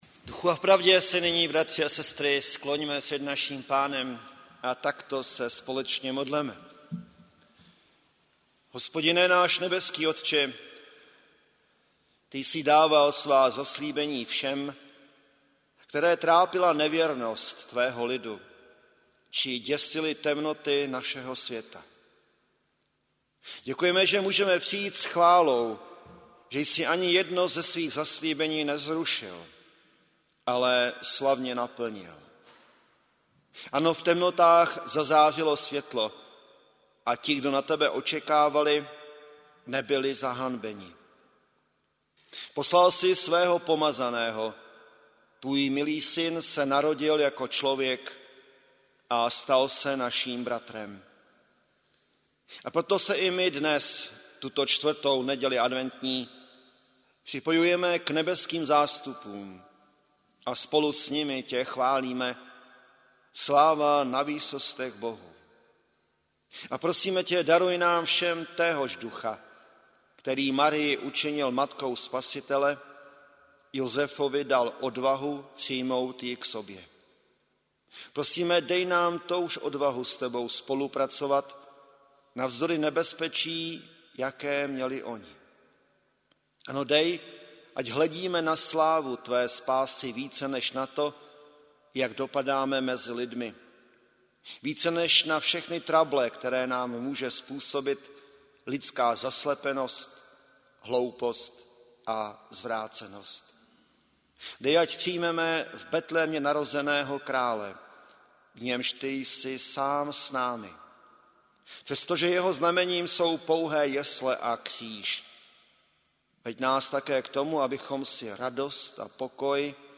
Čtvrtá adventní a Štědrý den